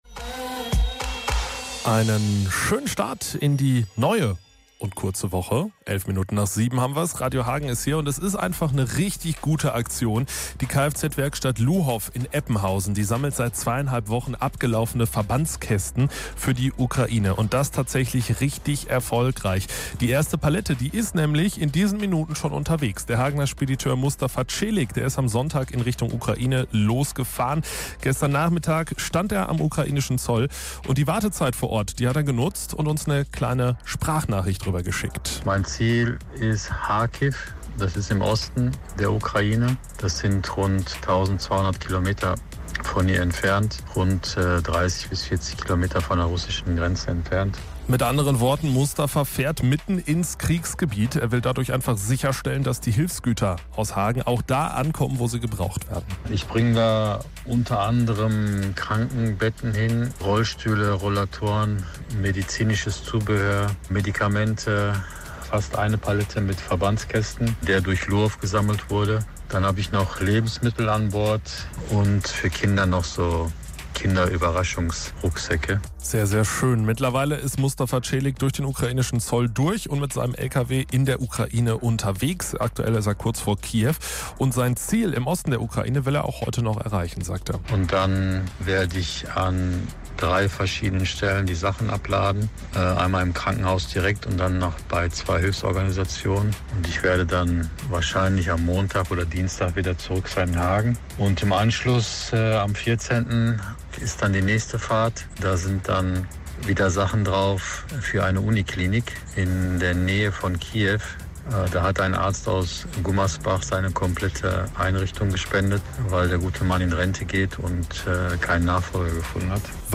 Gestern Nachmittag stand er am ukrainischen Zoll und hat die Wartezeit genutzt. Er hat mit uns über die Hilfslieferung gesprochen.